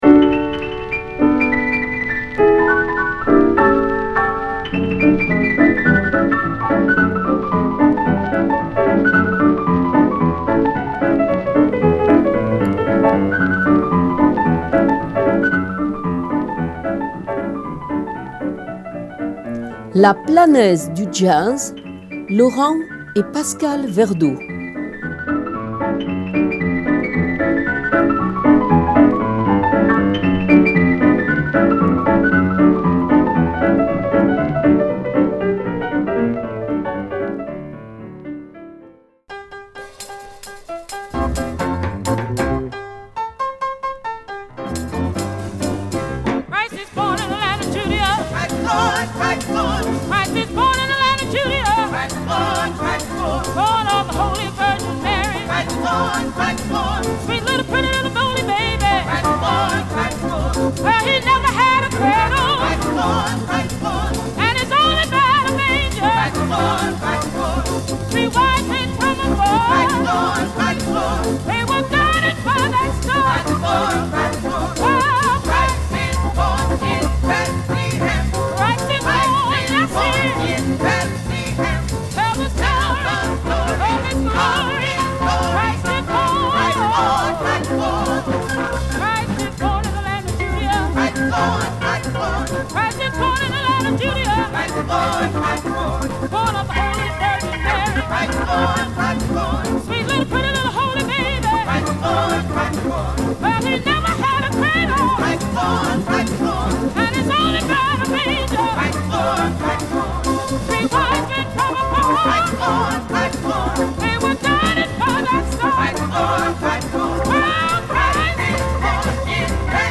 Emission sur le Jazz